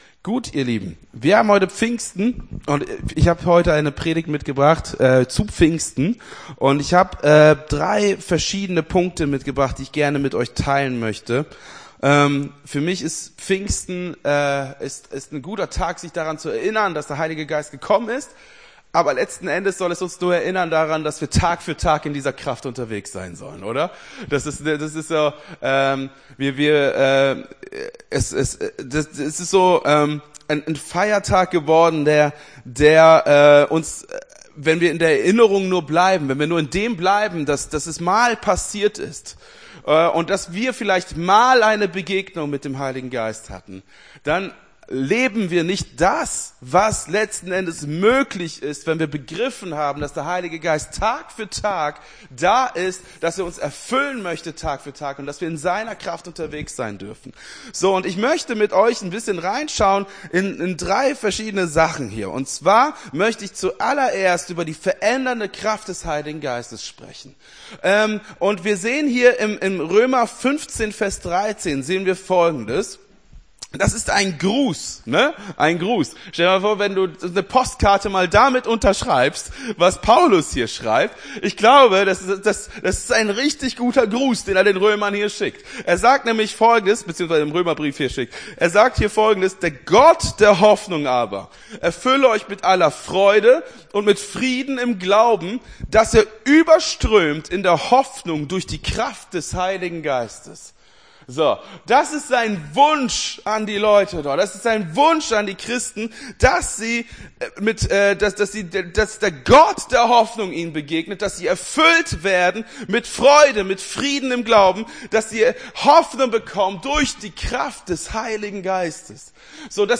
Gottesdienst 19.05.24 - FCG Hagen